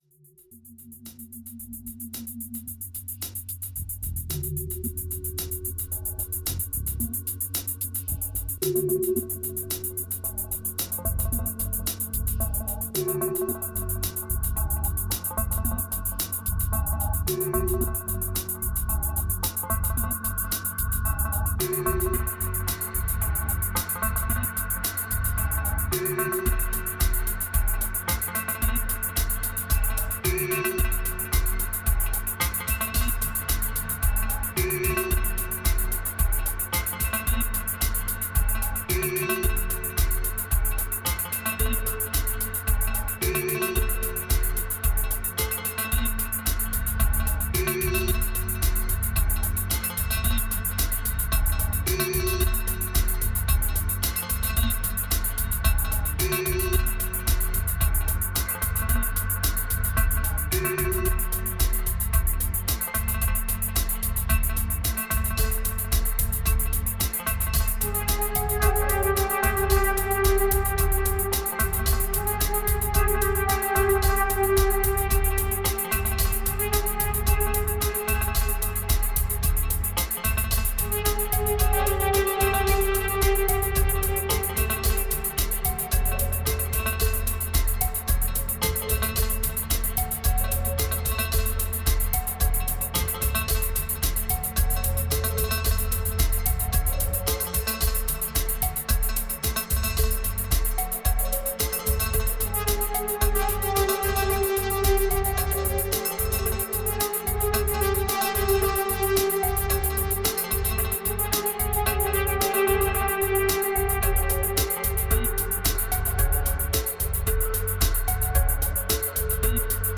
2318📈 - 53%🤔 - 111BPM🔊 - 2017-06-25📅 - 318🌟